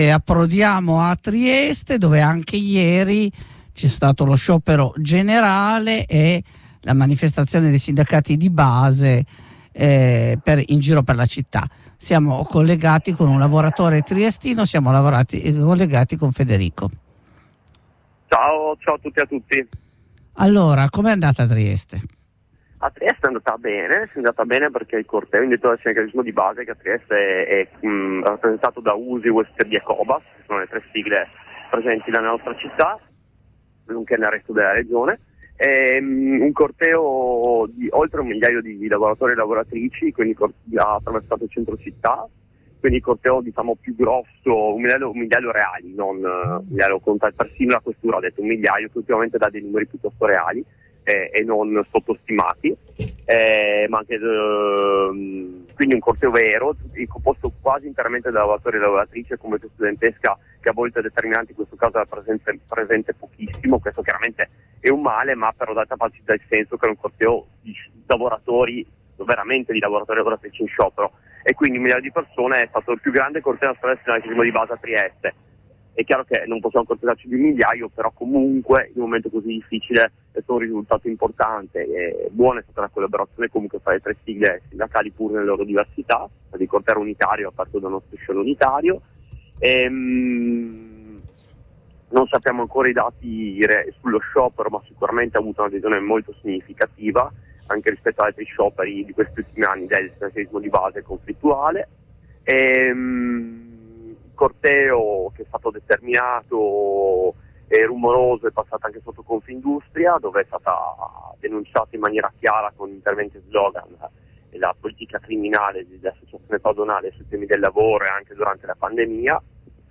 L’11 ottobre è stata una lunga giornata di sciopero e di lotta, che abbiamo provato a restituirvi ed analizzare con lavorator* che vi hanno preso parte a Torino, Firenze, Milano, Trieste, Prato…